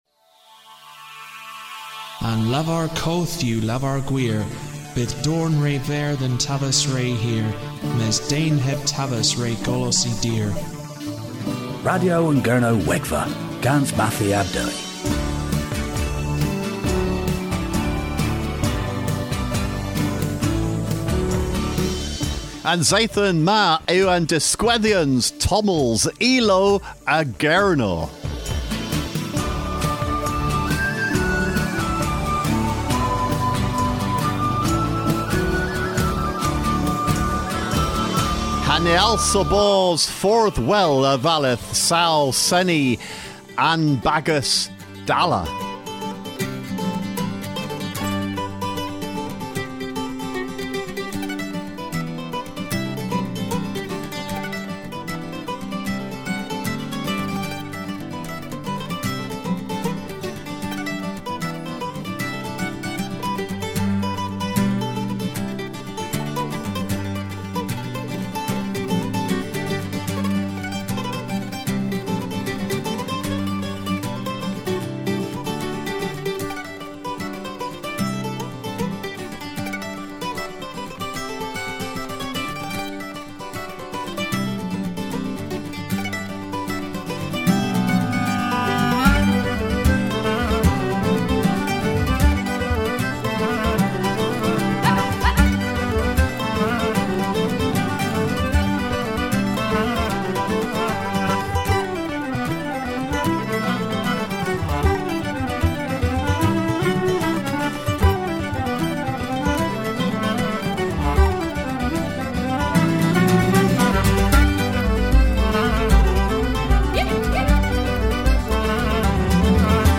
Fery spladn a vûsyk dhe’n Golowan.
An extravaganza of music for Midsummer.